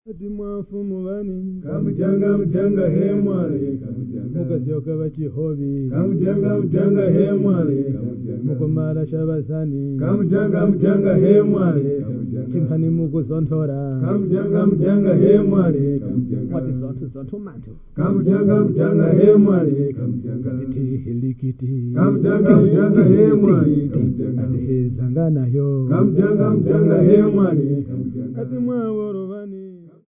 5 Tumbuka men
Folk music--Africa
Field recordings
Africa Zambia Mufulira f-za
Unaccompanied humorous song.